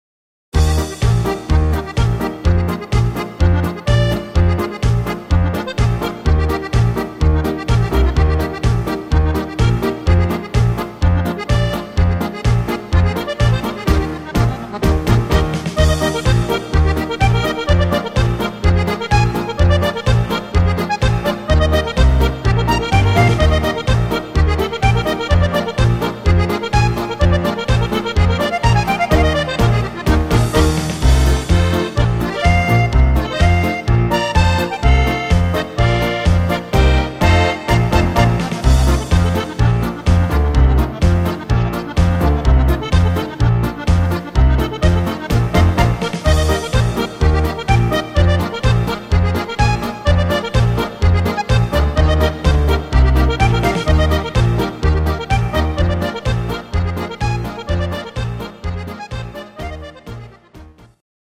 instr. Sopransax